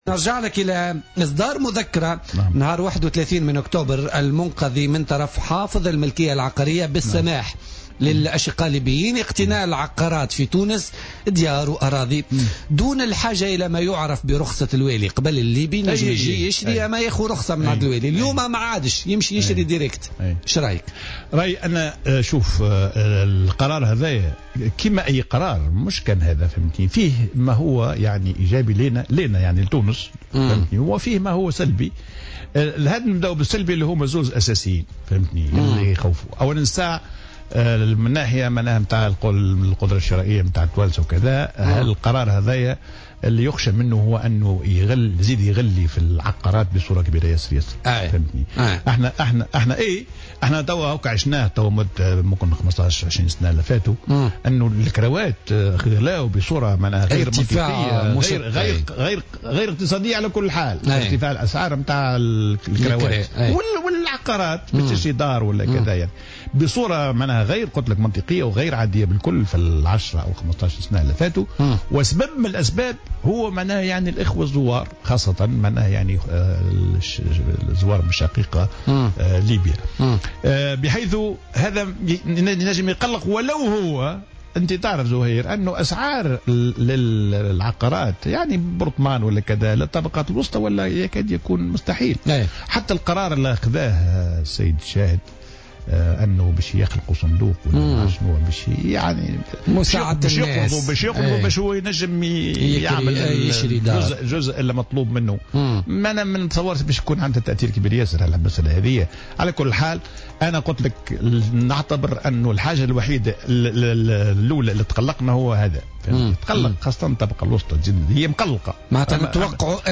وأضاف الديماسي خلال مداخلة له اليوم في برنامج "بوليتيكا" أن تمكينهم من شراء العقارات دون رقابة ودون رخصة الوالي سيطرح إشكالا آخرا يتمثل في تسرّب مجموعات "مشبوهة" إلى تونس.